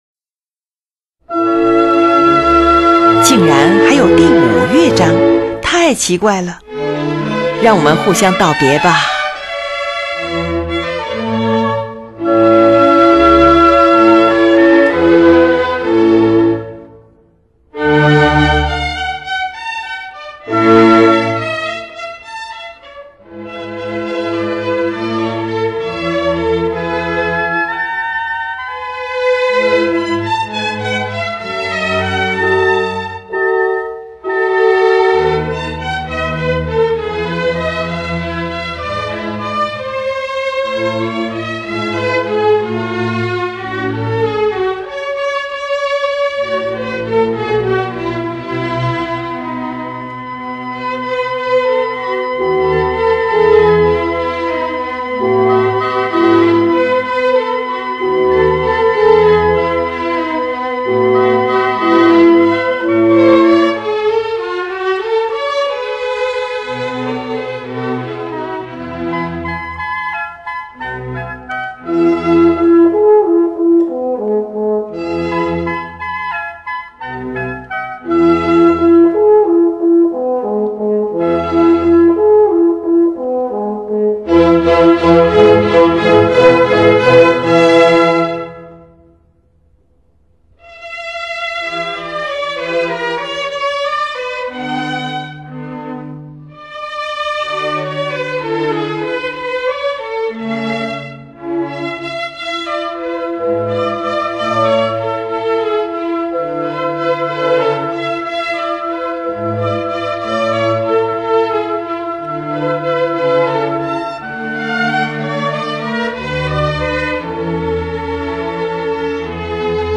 静谧的声音犹如一朵青云在炎炎夏日的天空中飘浮又漂浮，渐渐地，越来越远，看不见了！
各乐器依次停止演奏，演奏者吹灭各自的烛火离开房间。最后只剩两把小提琴。